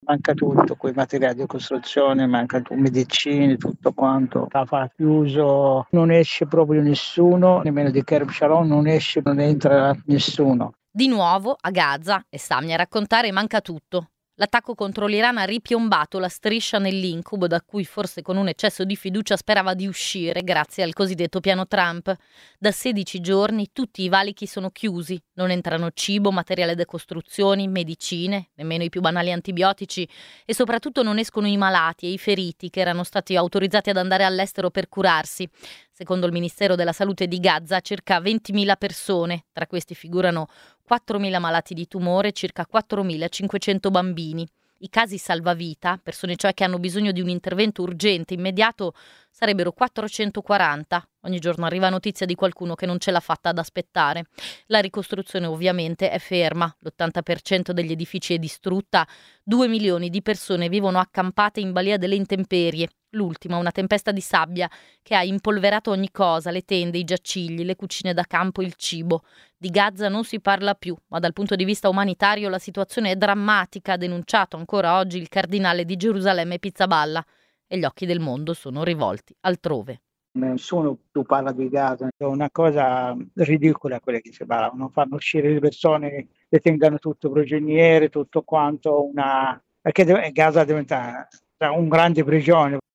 Il servizio integrale: